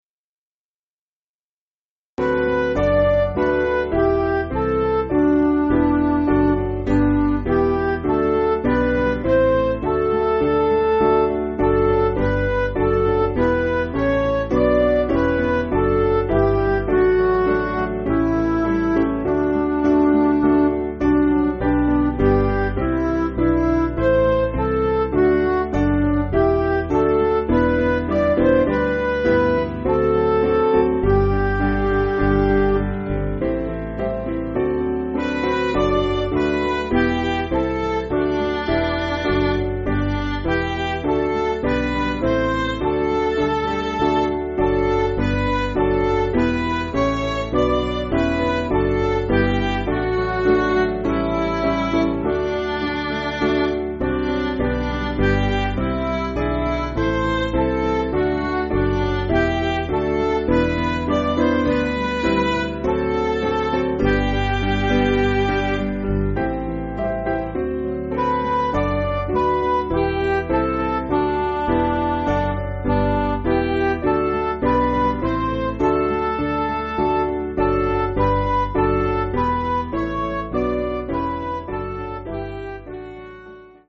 Piano & Instrumental
(CM)   5/G